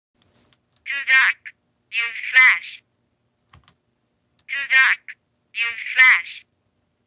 The Minolta Talker has a limited vocabulary but just enough to be annoying.
toodark.m4a